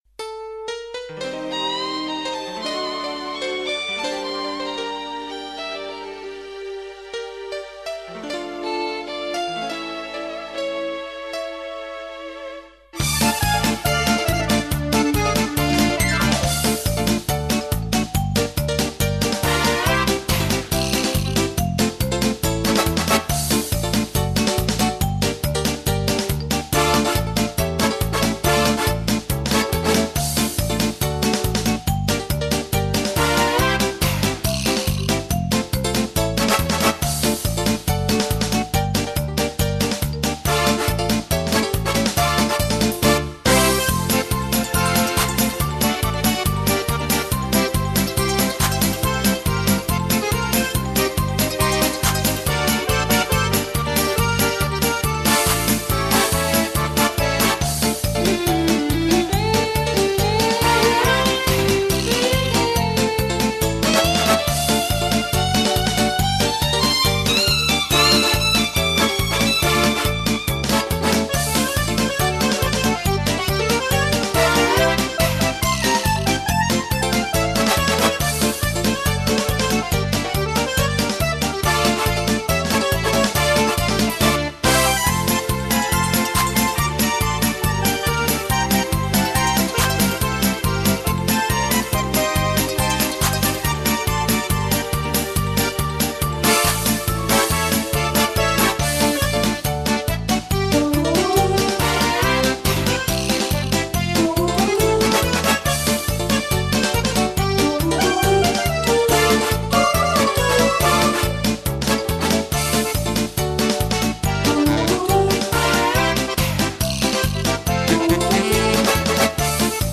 (Без припева)